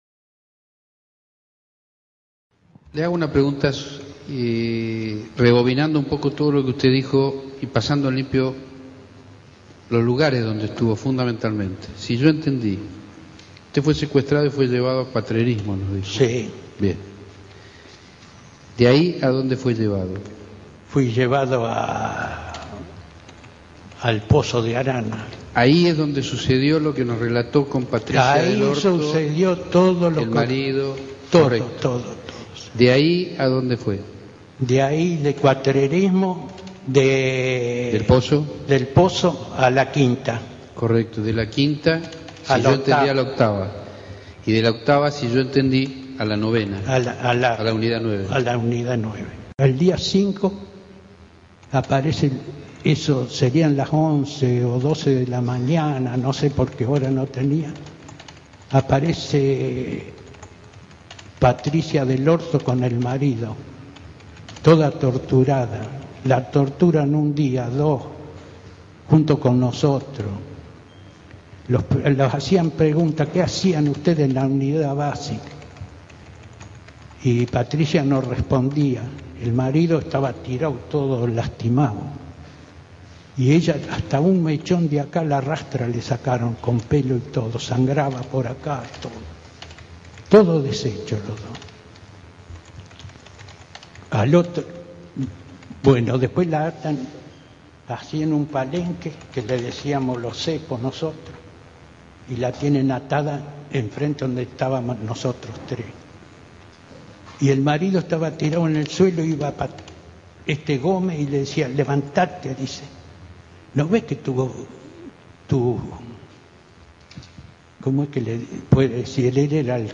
Testimonio
en el juicio contra Miguel Etchecolatz